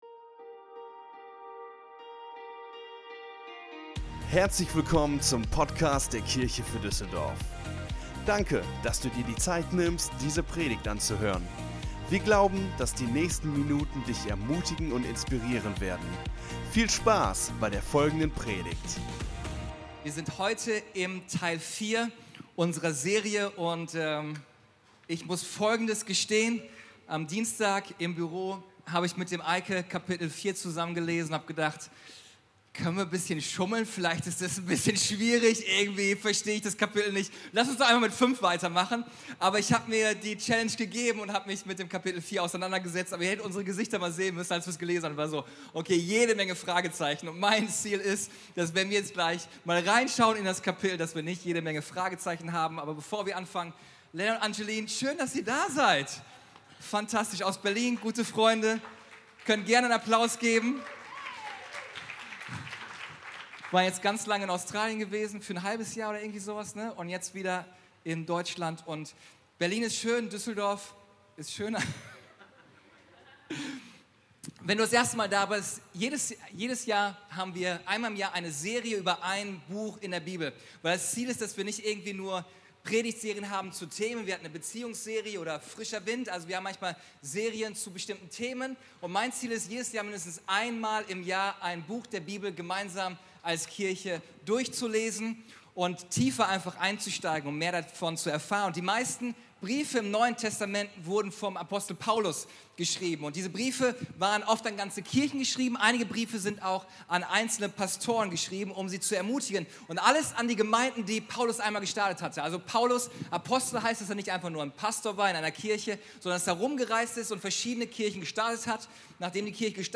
Der vierte Teil unserer Predigtreihe "GALATER".